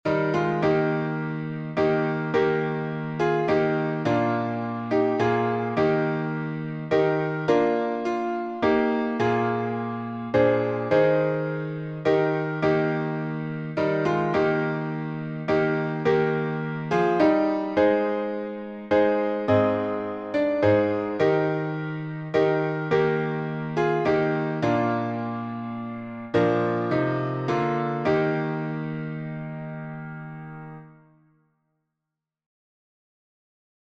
Key signature: E flat major (3 flats) Time signature: 6/4
Just_As_I_Am_Eflat.mp3